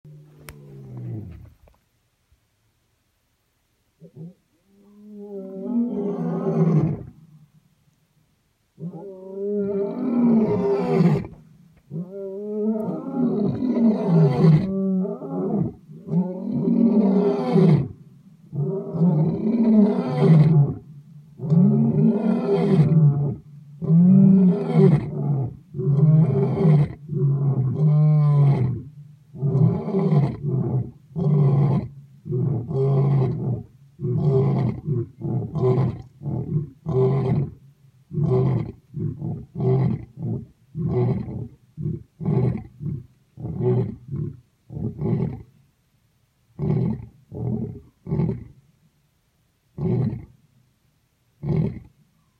On a good day in the savannah, lions and elephants have sounds that travel 8km and 10km, respectively.
lions-chorusing.m4a